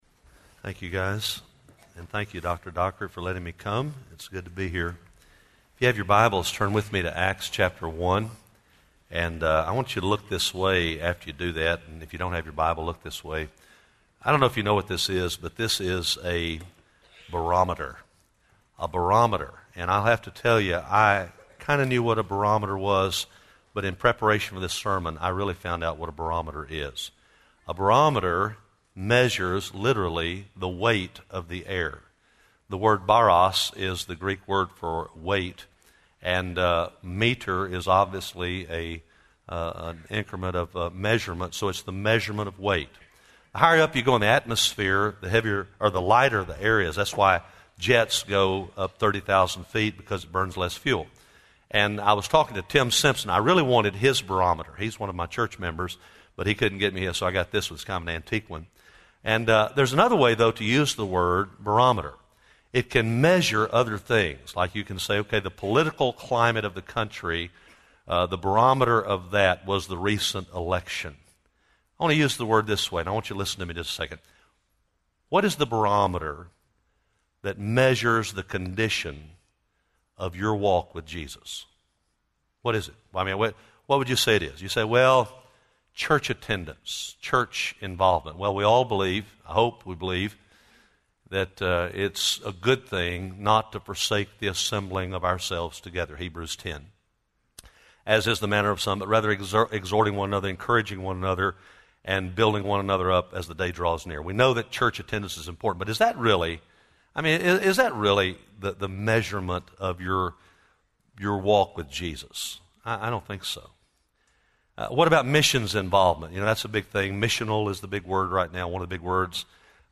Address: "Prayer" from Acts 2:42 Recording Date: Sep 7, 2011, 10:00 a.m. Length: 30:36 Format(s): MP3 ; Listen Now Chapels Podcast Subscribe via XML